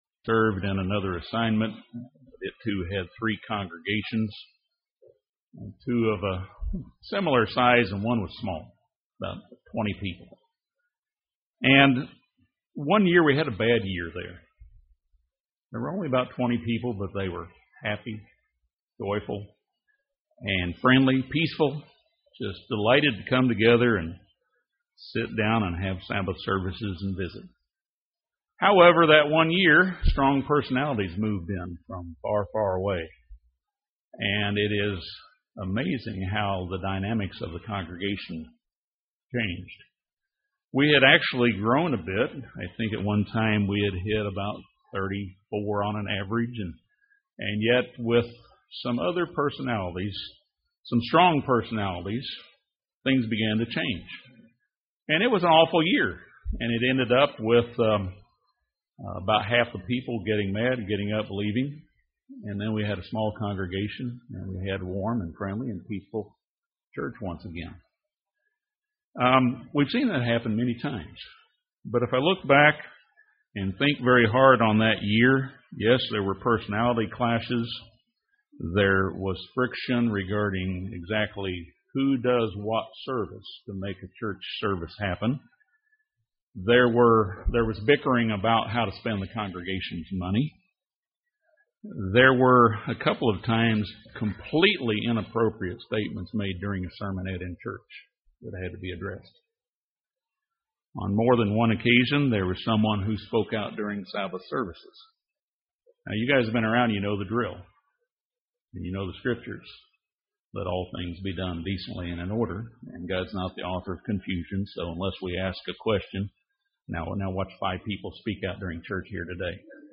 This sermon addresses several of his statements regarding unity in the Body. He reminded brethren that they are members one of another.